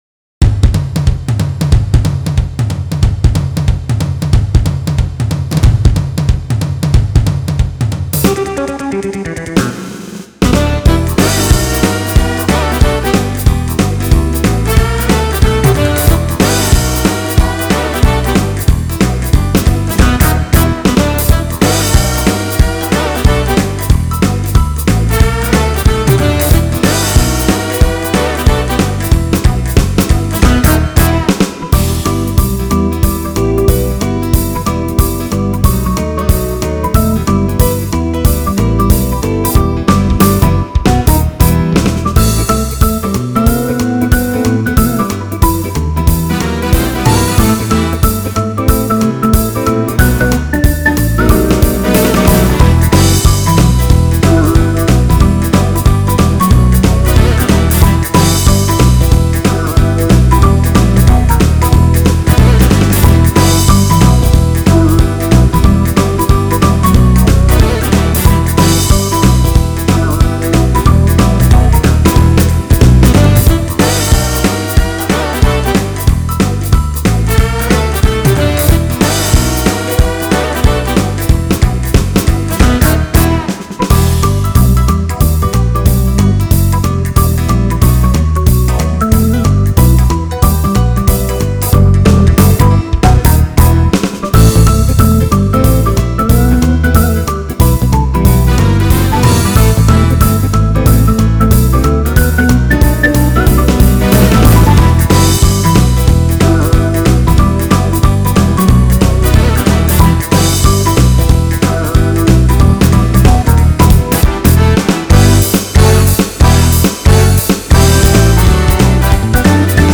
th6YZTC292  Download Instrumental
The backing tracks are on the “Jazz” list.